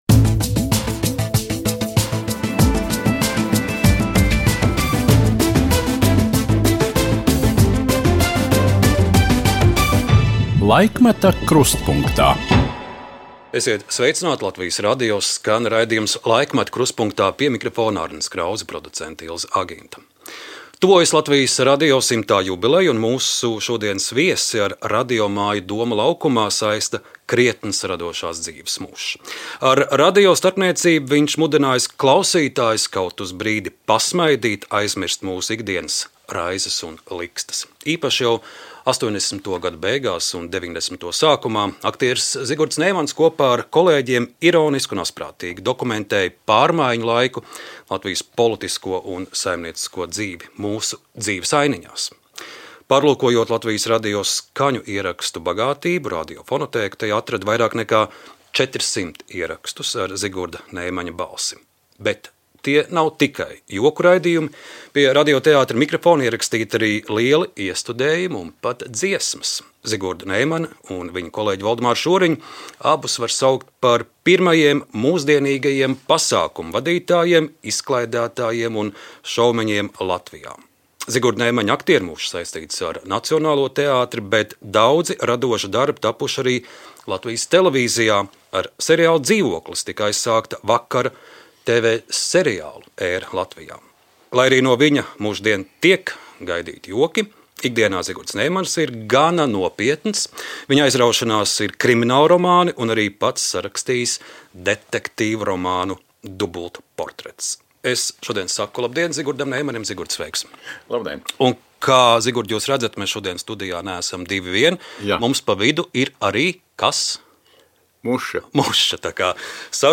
Teātris kļuvis par tādu sintētisku mākslu, kas nesastāv tikai no aktiera spējām, un man tas vairs nesniedz tādu gandarījumu – saka aktieris Zigurds Neimanis. Par jokiem no skatuves agrāk un tagad, īso kinokarjeru un kā veidoja leģendāro seriālu "Dzīvoklis", kāpēc raksta grāmatas un kas kopīgs ar hokejistu Balderi saruna ar Zigurdu Neimani Laikmeta krustpunktā.